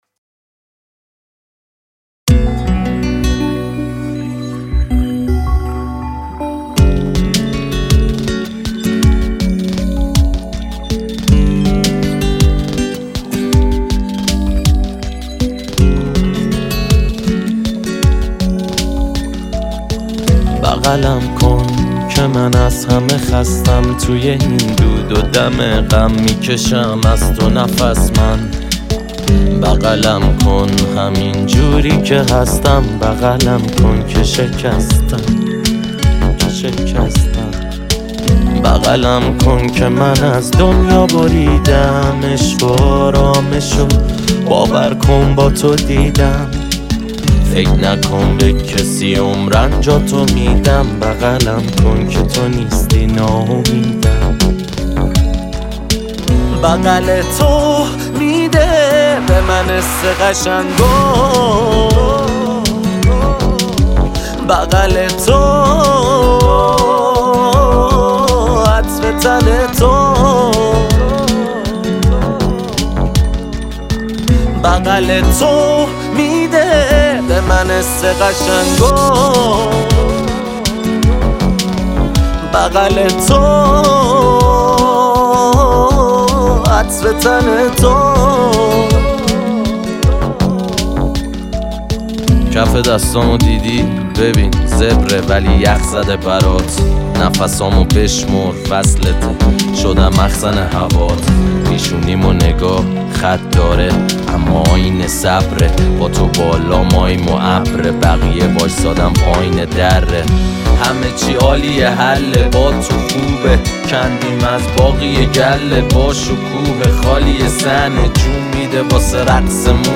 آراَندبی
اهنگ ایرانی